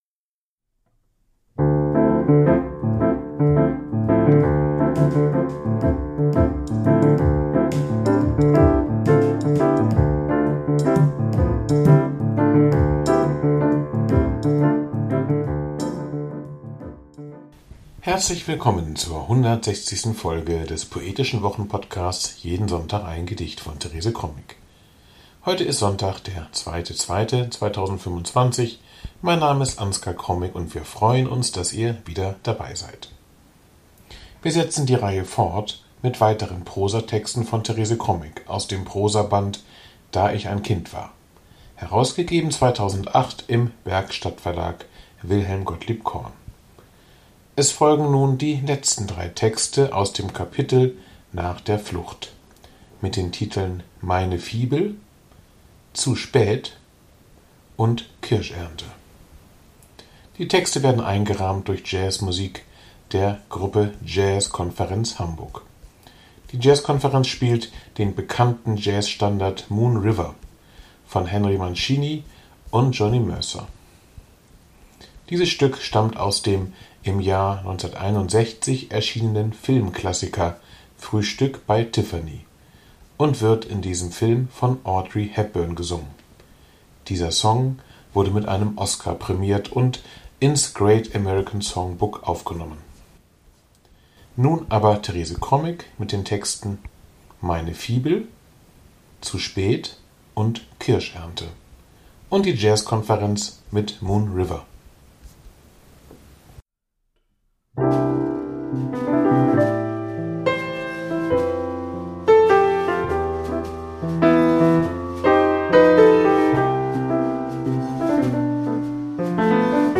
Die Jazzkonferenz spielt den bekannten Jazz-Standard "Moon River" von Henry Mancini und Jonny Mercer.